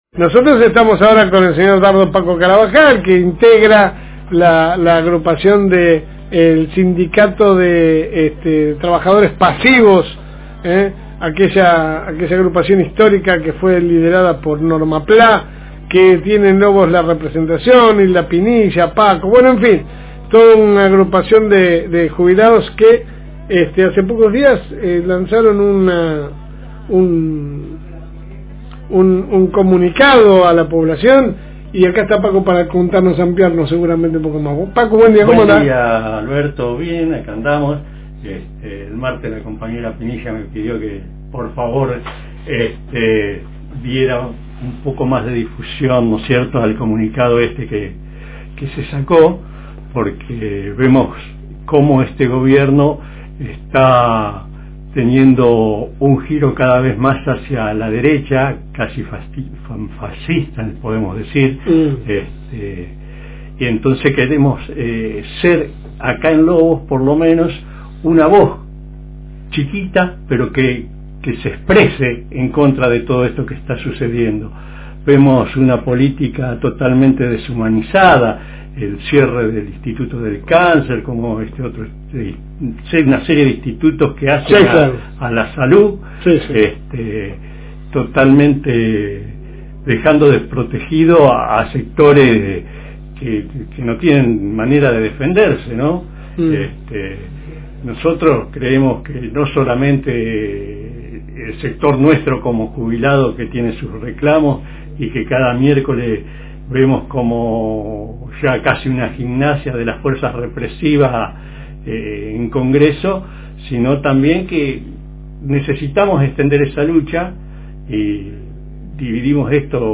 Paso por los estudios de la FM Reencuentro